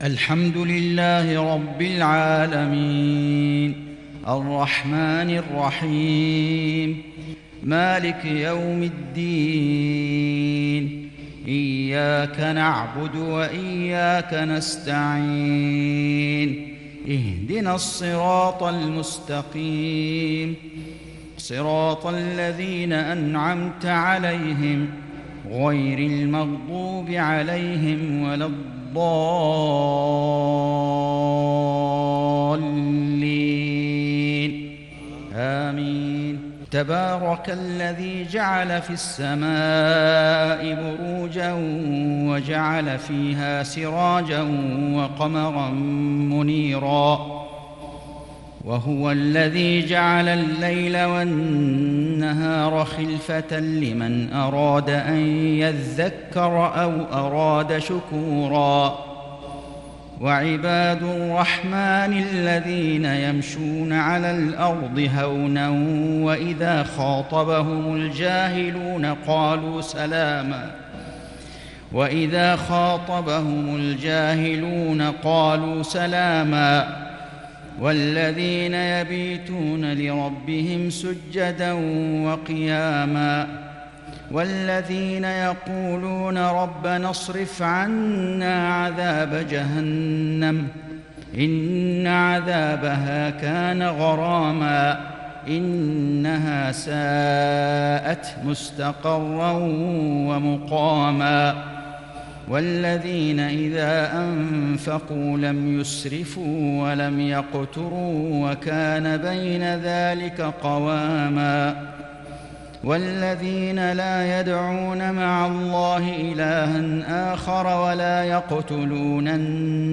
صلاة العشاء للشيخ فيصل غزاوي 25 صفر 1442 هـ
تِلَاوَات الْحَرَمَيْن .